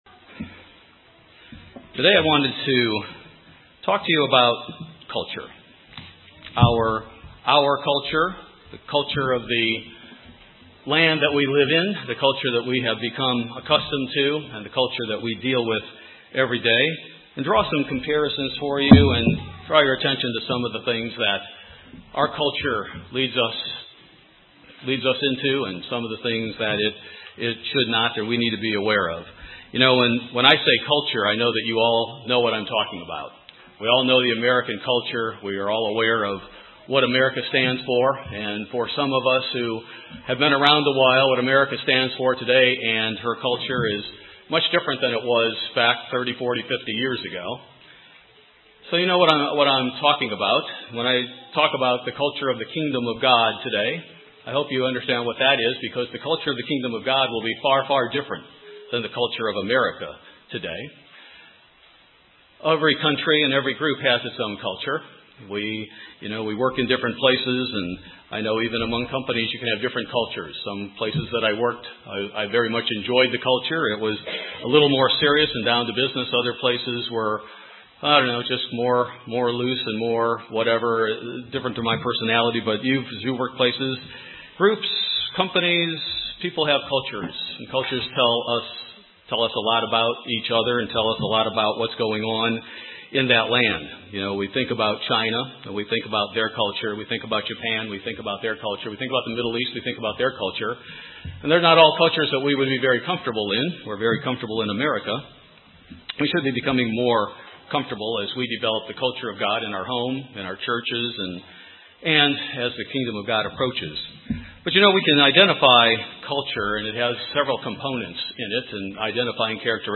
In this sermon, one element of the culture that defines America is discussed and compared with the culture of the Kingdom of God.